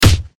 punch2.ogg